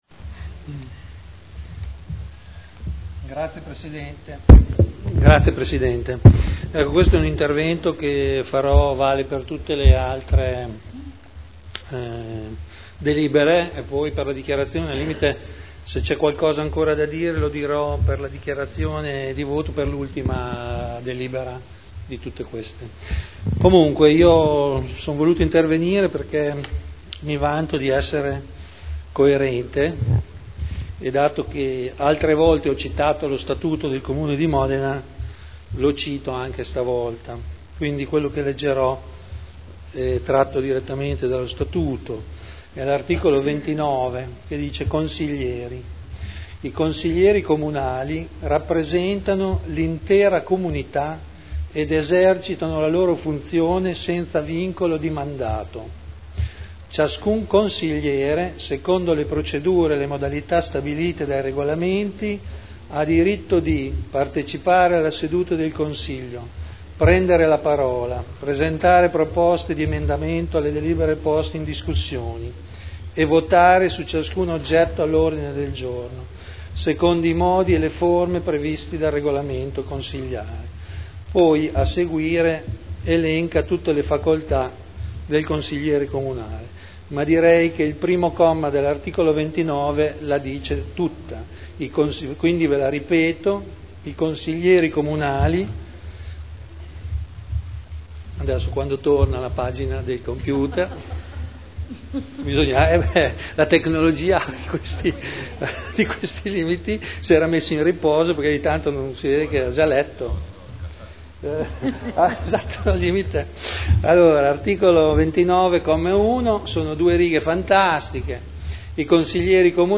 Seduta del 12 dicembre Commissione consiliare permanente Programmazione e Assetto del Territorio, Sviluppo economico e Tutela ambientale – Modifica Dibattito